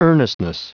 Prononciation du mot earnestness en anglais (fichier audio)
Prononciation du mot : earnestness